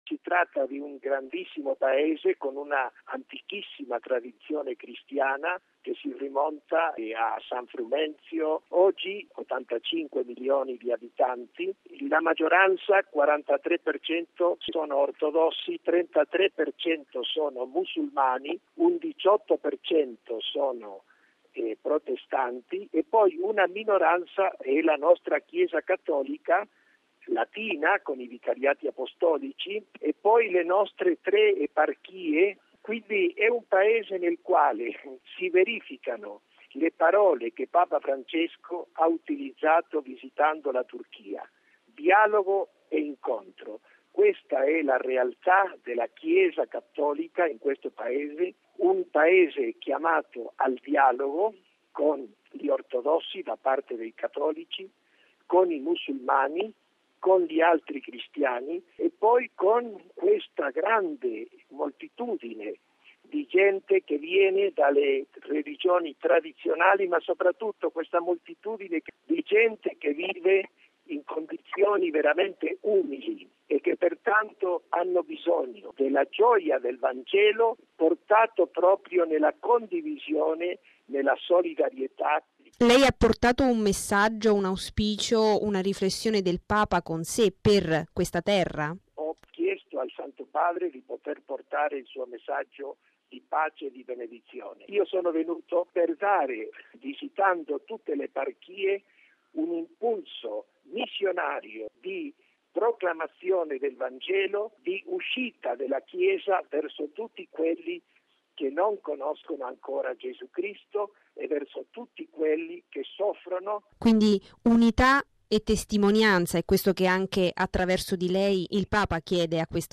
"Un Paese aperto al dialogo, con una Chiesa in prima fila nel sostegno agli ultimi”: questa in sintesi è l’Etiopia nelle parole del cardinale Sandri.